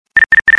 Nextel bleep X 3